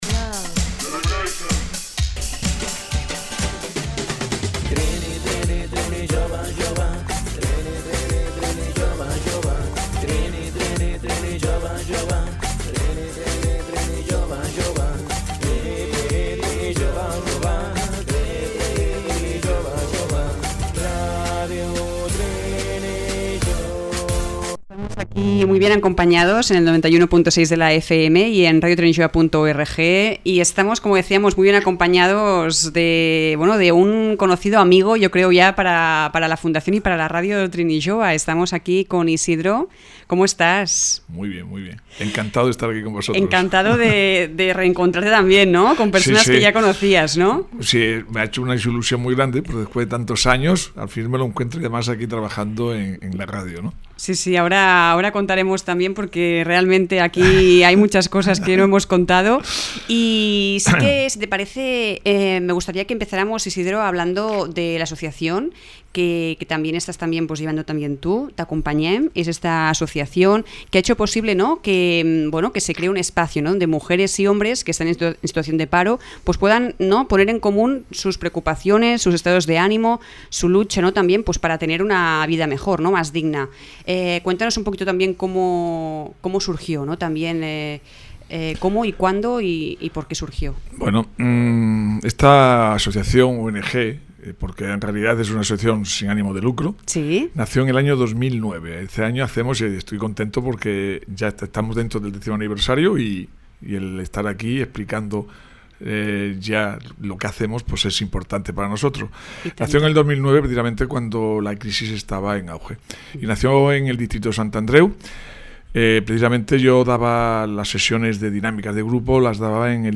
ENTREVISTA RADIO TRINIJOVE | T'acompanyem
ENTREVISTA-RADIO-TRINIJOVE.mp3